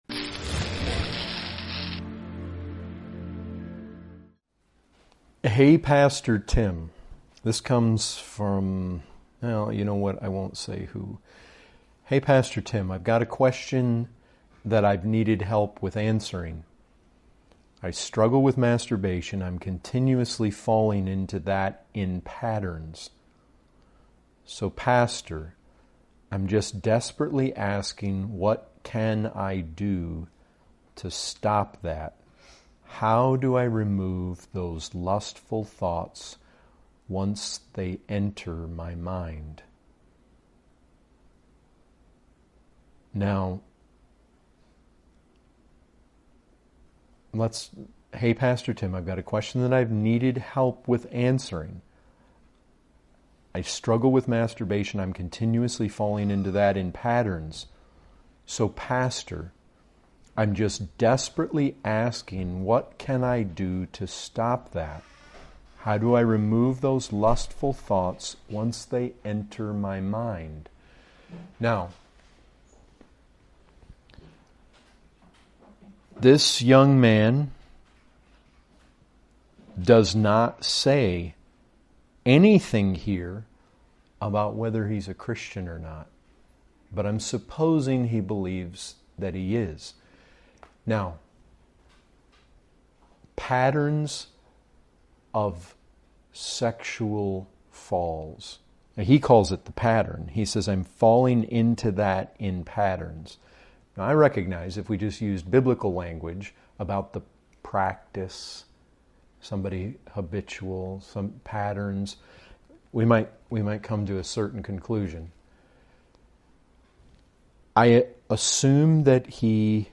Category: Questions & Answers